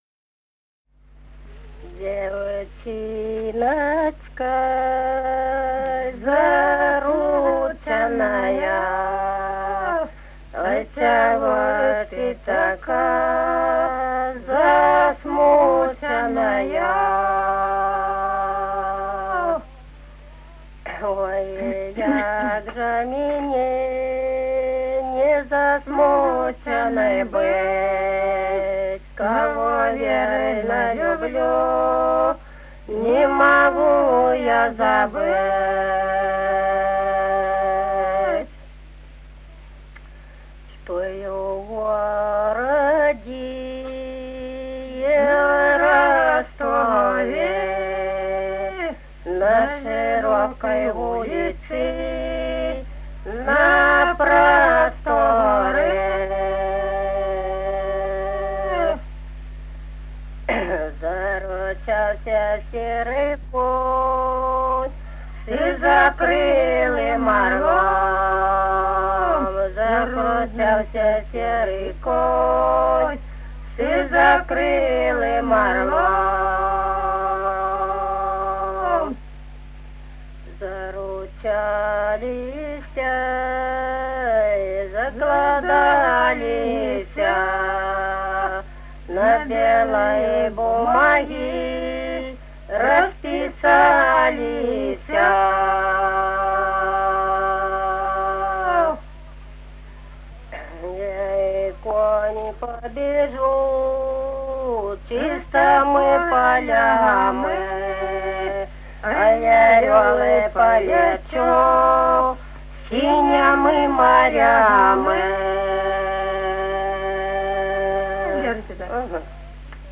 Народные песни Стародубского района «Девчиночка зарученая», лирическая.
1959 г., с. Чубковичи.